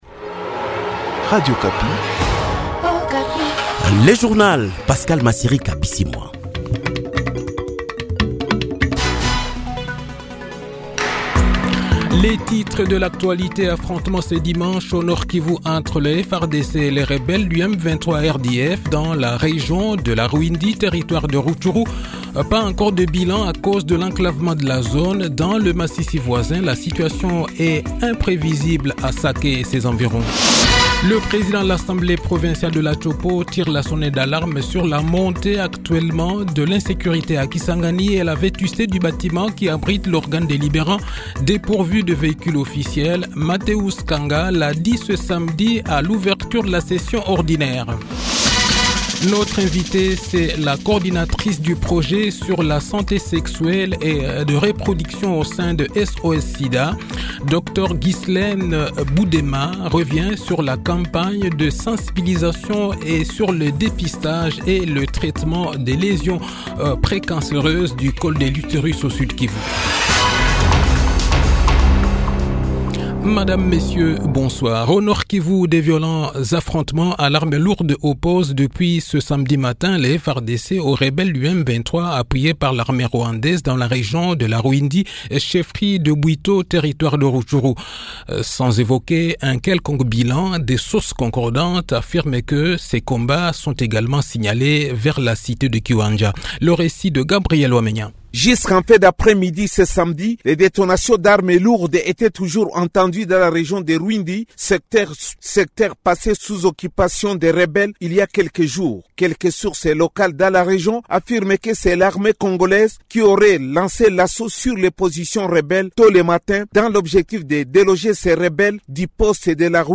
Le journal de 18 h, 30 mars 2024